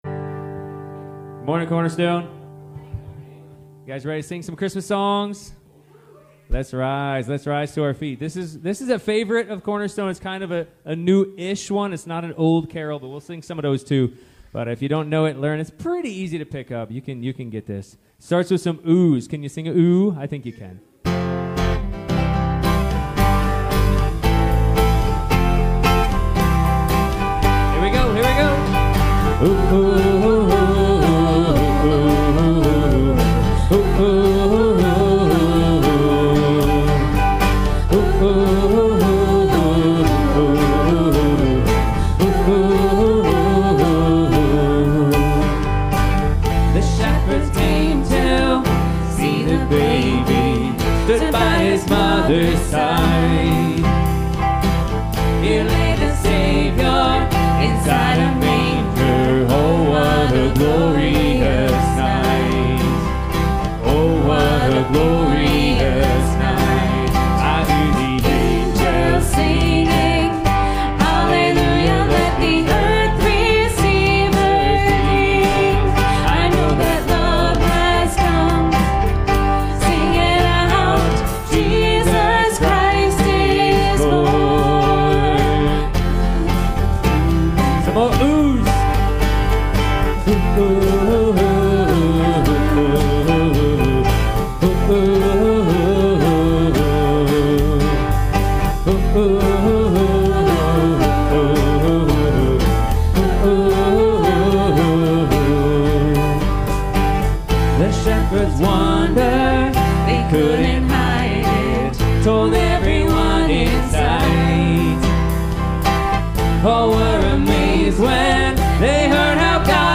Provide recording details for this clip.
Series: CCC Sermons Passage: Matthew 1:18-25 Service Type: Sunday Morning « How did we get here?